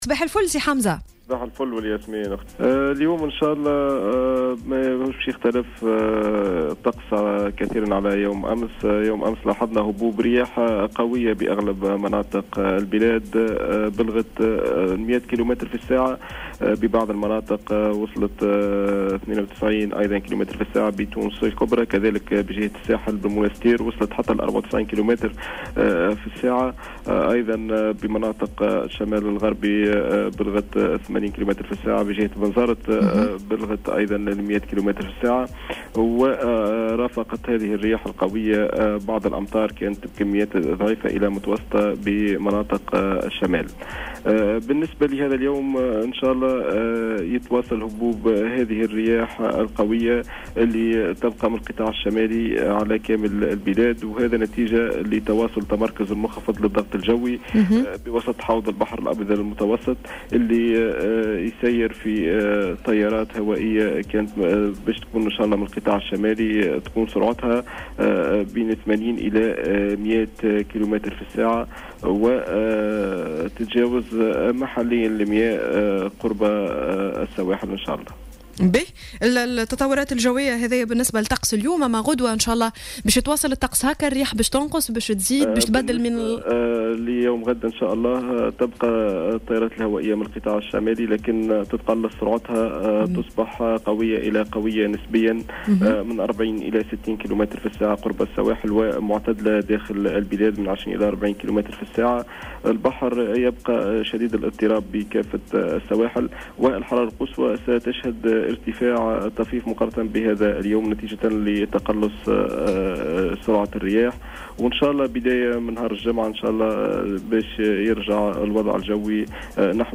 وقال في اتصال هاتفي مع "الجوهرة اف أم" في "صباح الورد" إن سرعة الرياح بلغت منذ يوم أمس 100 كلم في الساعة بتونس الكبرى و92 كلم بجهة الساحل و80 كلم في مناطق الشمال الغربي و100 كلم في بنزرت كما رافقتها بعد الأمطار الضعيفة والمتوسطة بمناطق الشمال.